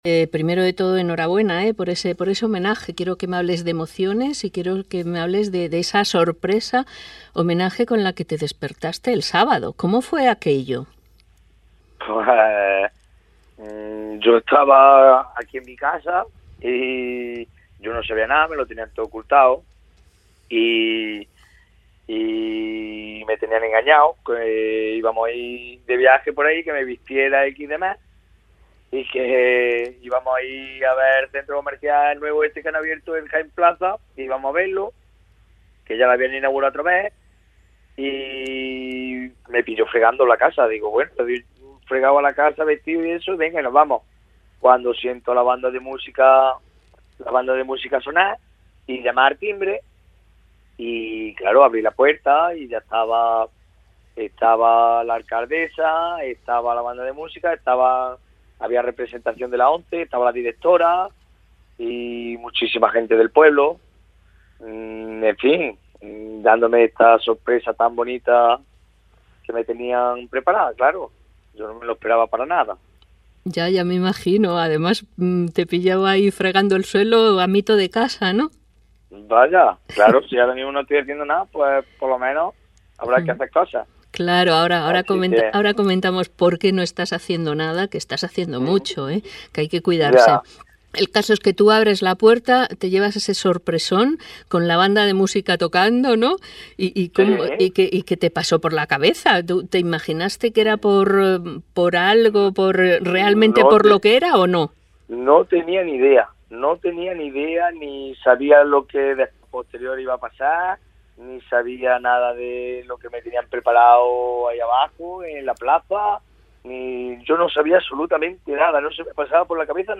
Fragmento de conversación formato MP3 audio(2,64 MB)